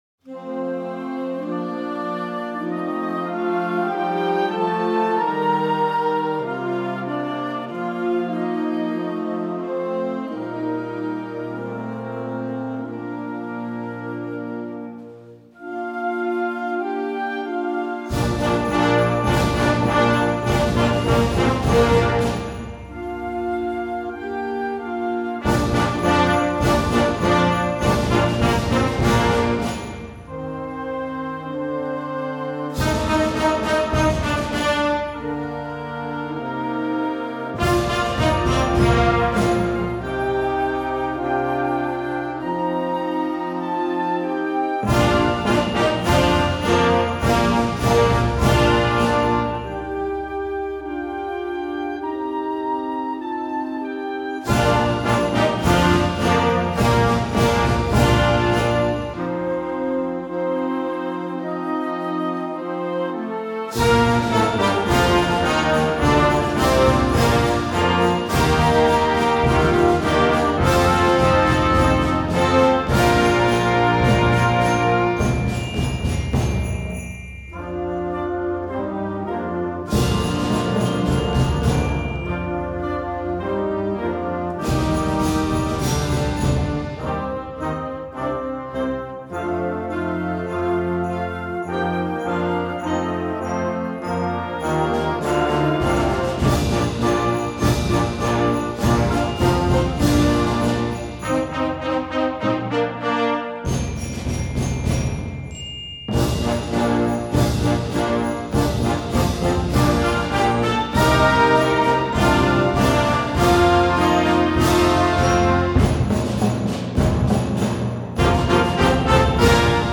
Gattung: Weihnachtslied für Jugendblasorchester
Besetzung: Blasorchester